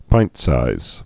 (pīntsīz) also pint·sized (-sīzd)